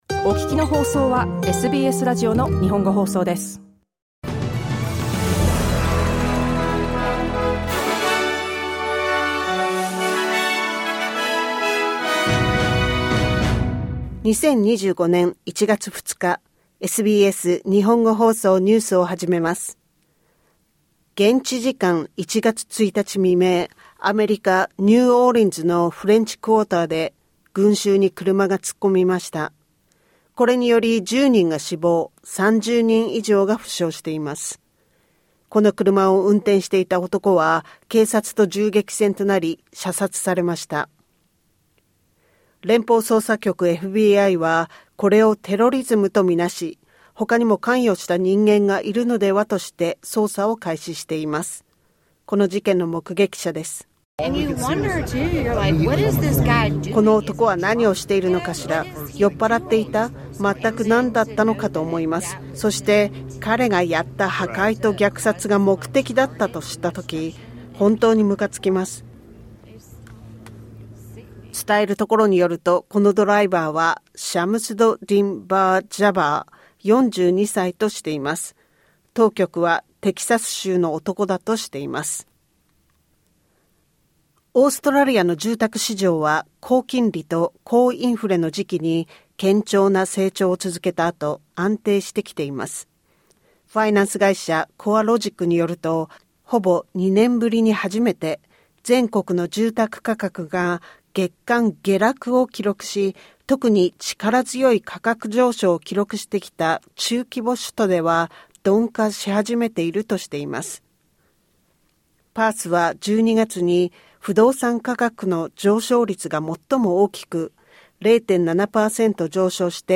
午後１時から放送されたラジオ番組のニュース部分をお届けします。